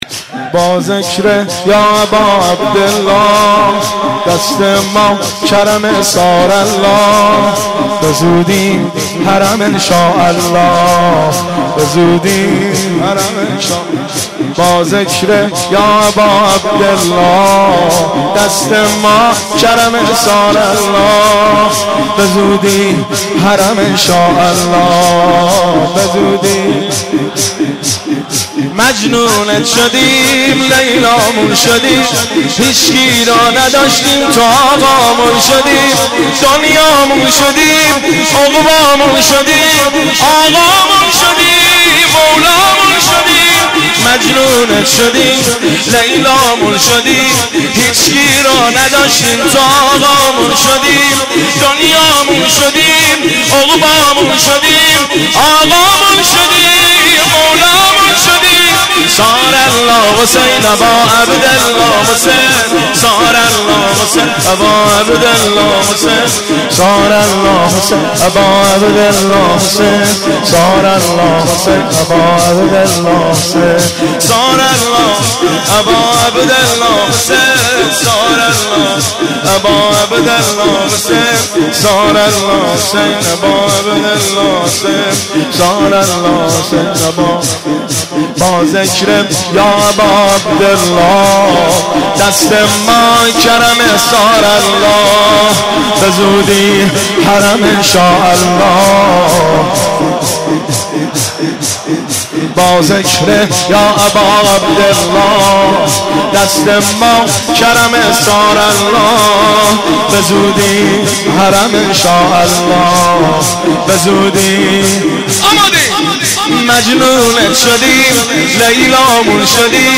مناسبت : شب بیست و ششم رمضان
قالب : شور